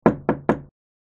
心地よい木のドアが三回ノックされる音。
木のドアを三回ノックする音 着信音